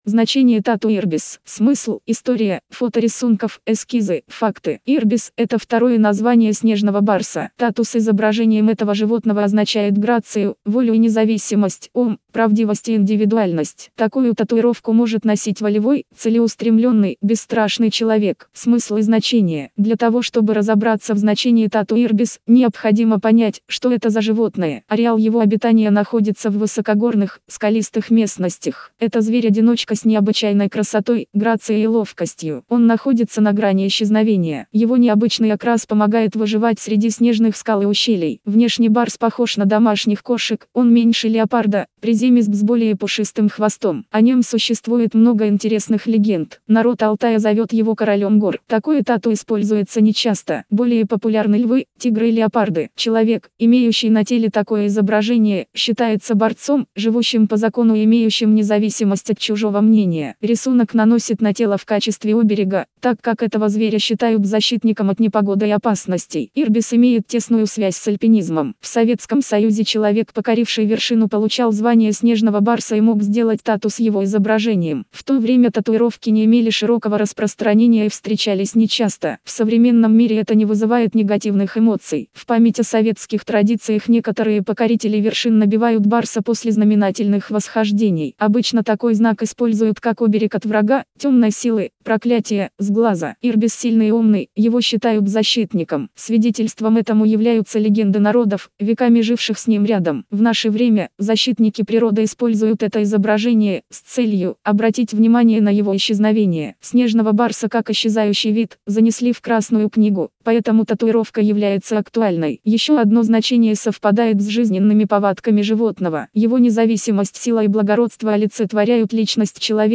Значение-тату-Ирбис-аудио-версия-статьи-для-сайта-tattoo-photo.ru_.mp3